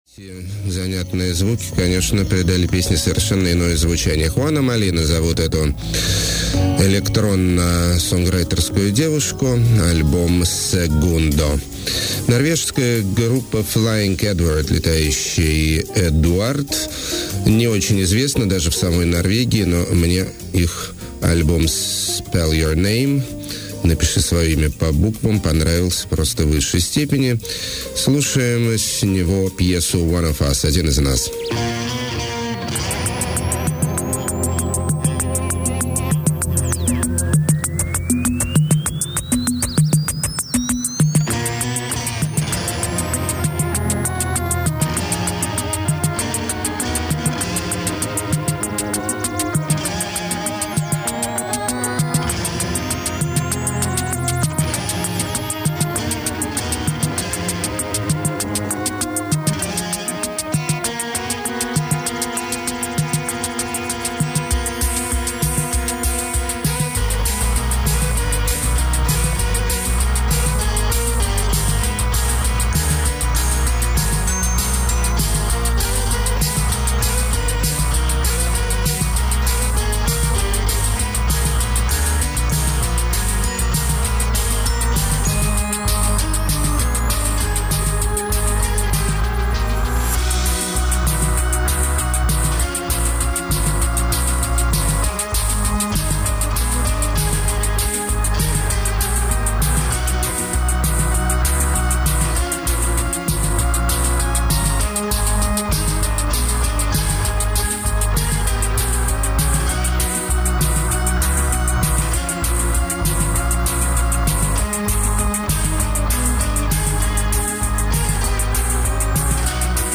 latin boleros under electronic treatment
shamelessly bourgeoise jazz
southern soft techno
college rock with post-rockish sound
gothic pop
trippy guitar rock
nu be bop
downtempo pioneers display conceptual ambitions